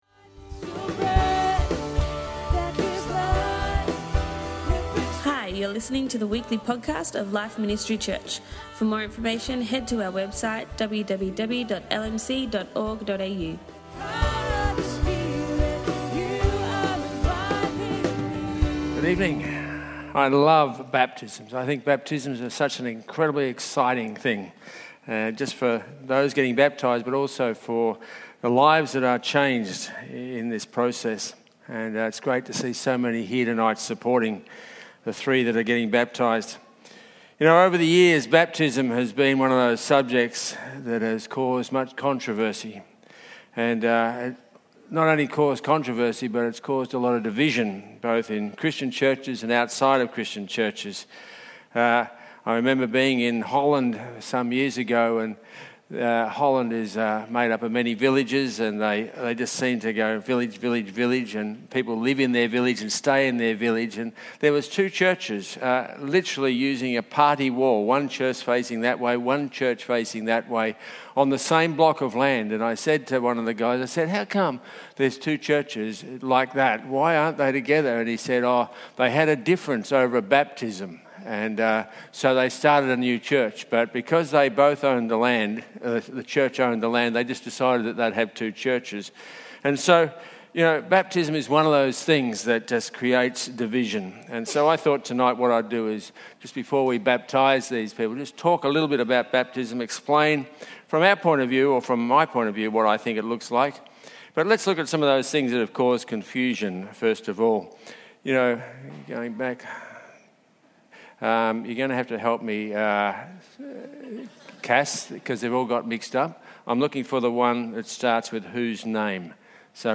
A Rite of Passage (Baptism Service)